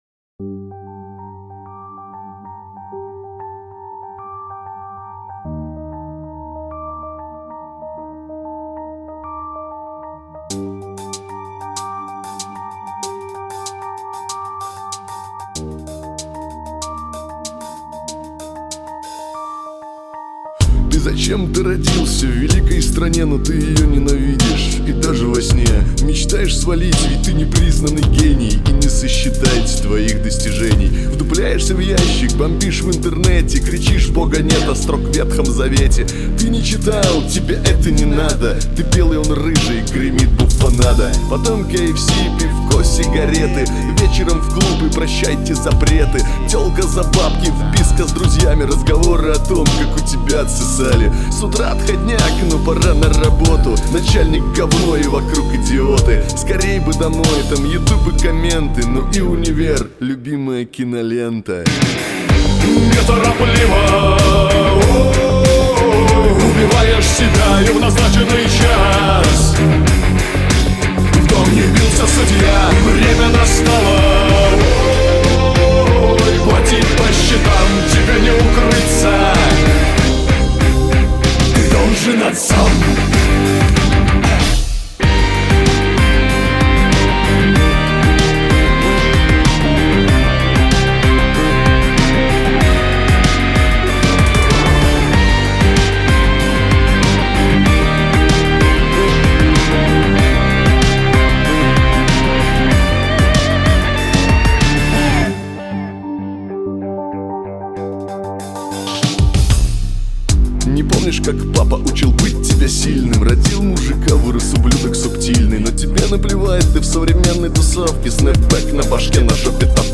Synth Rock
По стилистике и аранжиру у меня получился винегрет, так что "синт рок" в названии темы - это больше для условности (правила есть правила).